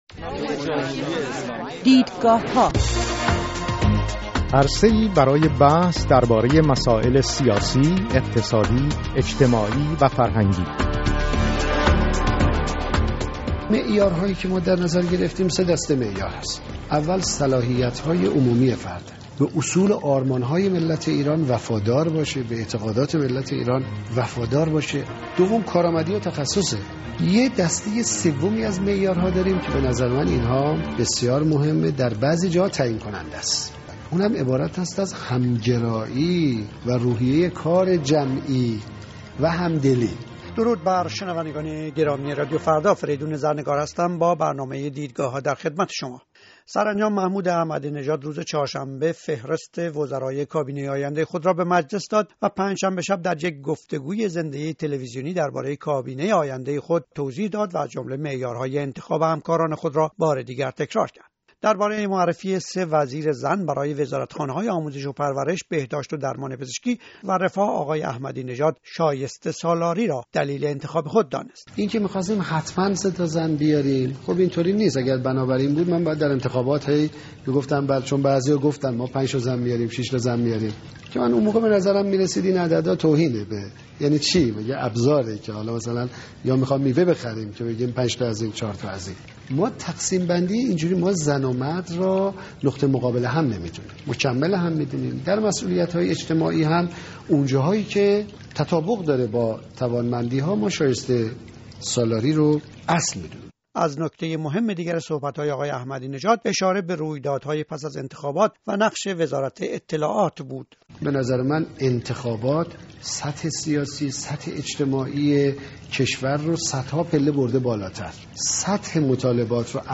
میزگرد